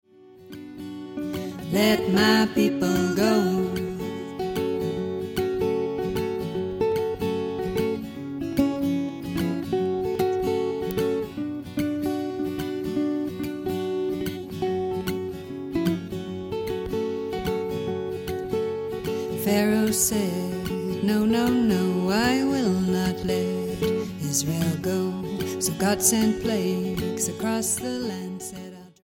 STYLE: Roots/Acoustic
1960s blues folk vein